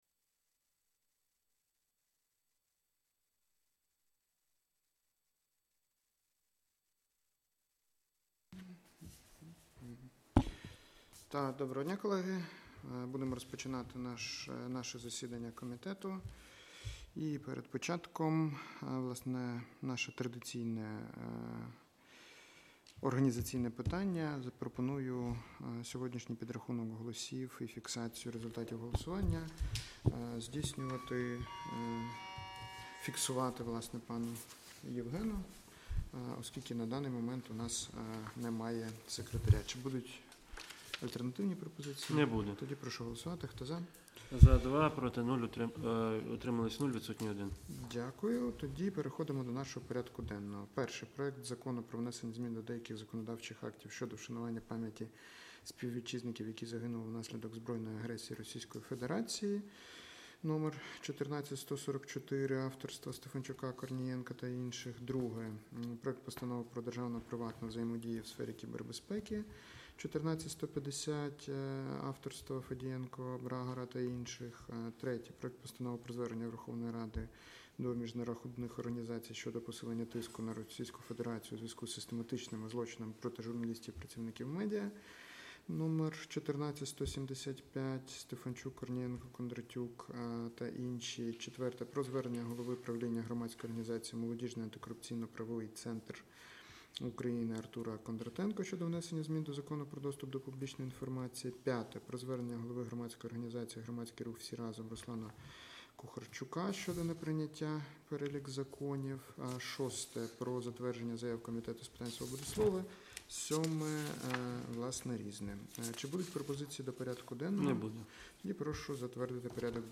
Аудіозапис засідання Комітету від 4 листопада 2025р.